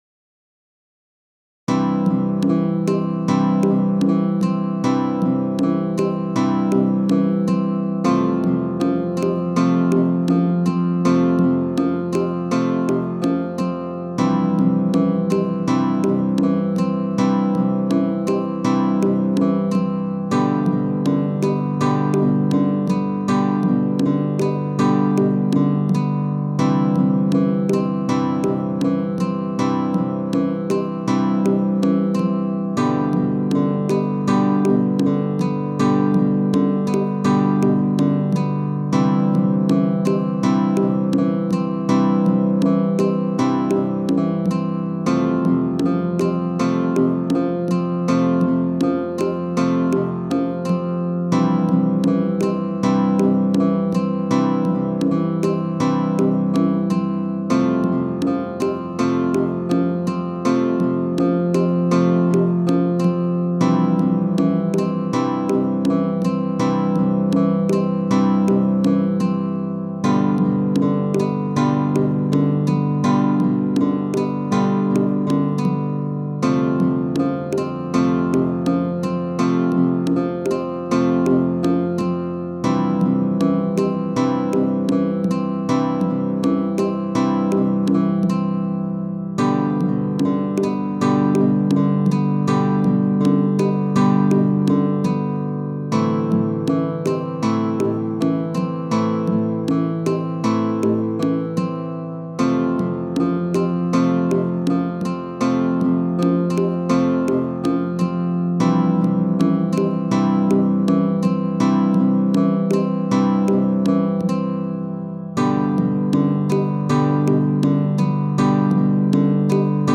BGMですね。